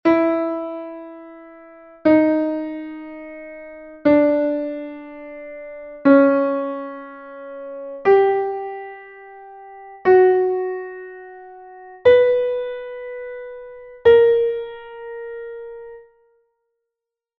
Exercise 2: descending chromatic semitones.
Now you have got descending chromatic semitones.
entonacion_semitonos_cromaticos_descendentes.mp3